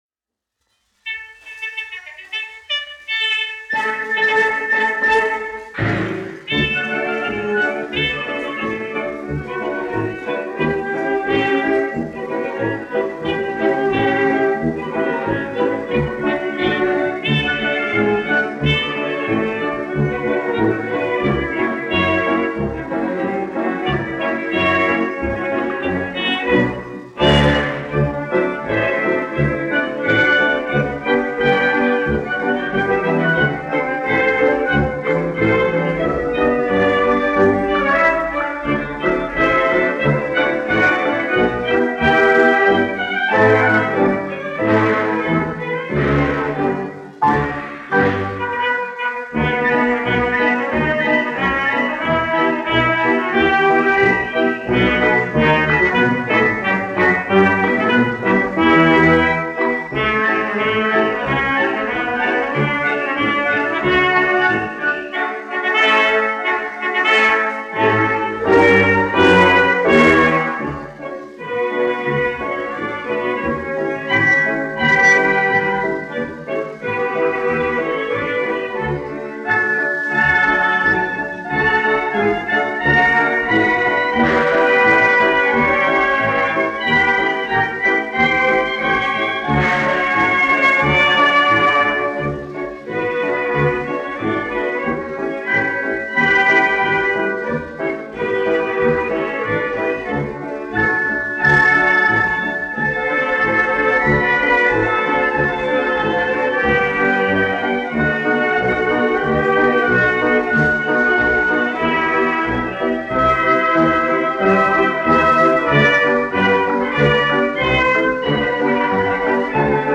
1 skpl. : analogs, 78 apgr/min, mono ; 25 cm
Orķestra mūzika, aranžējumi
Skaņuplate